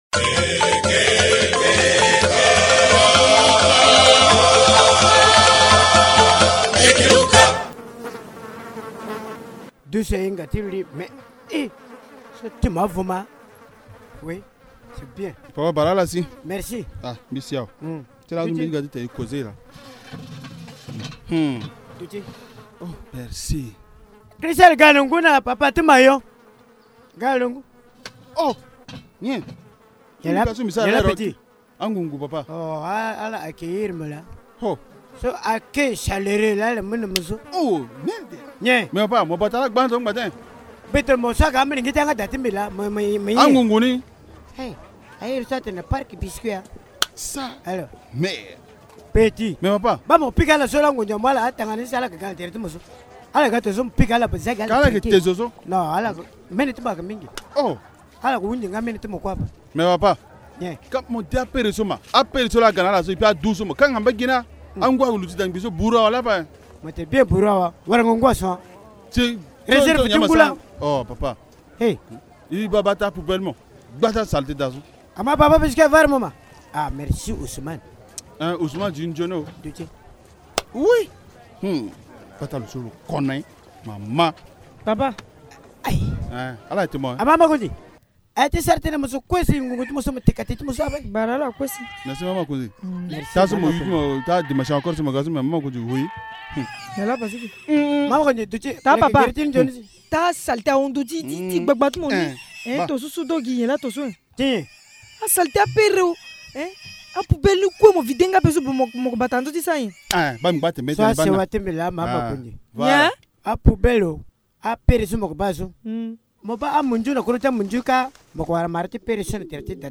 La prolifération des moustiques inquiètent les comédiens du village Linga